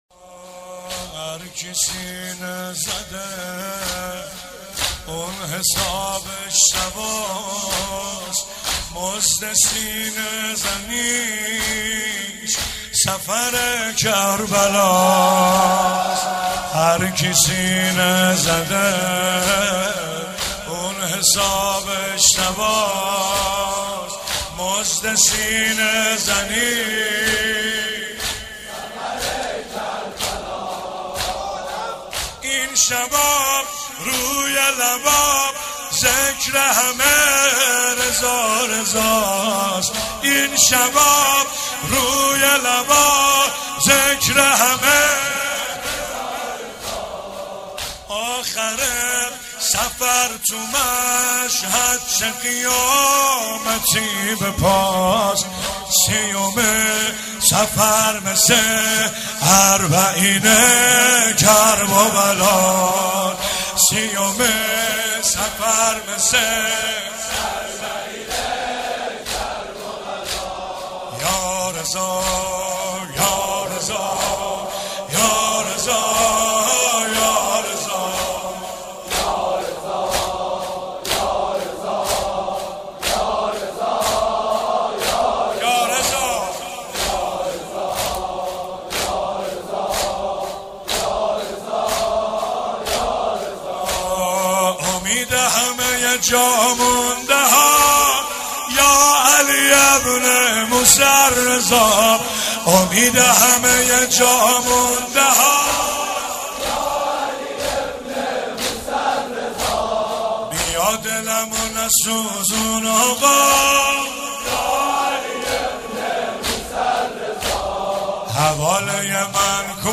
مناسبت : شهادت امام رضا علیه‌السلام
قالب : زمینه